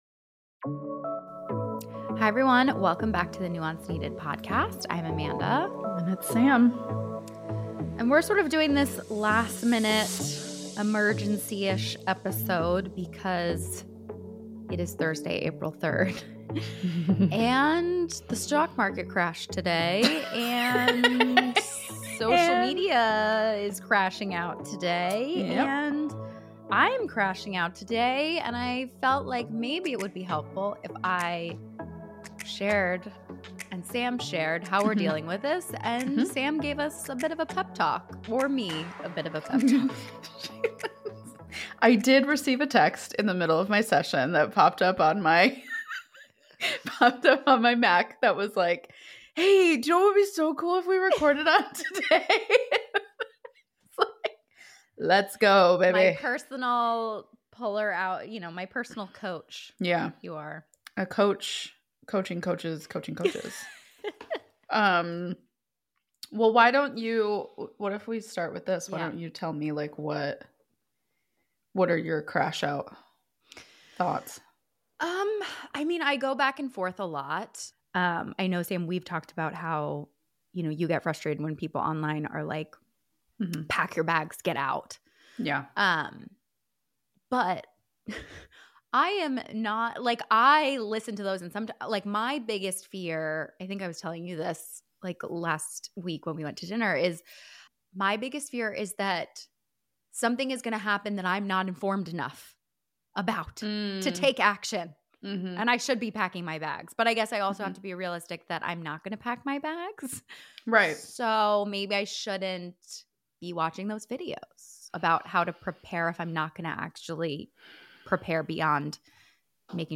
BONUS: Pep Talk About Economic & Political Uncertainty
We are here today with a bit of a last minute casual episode.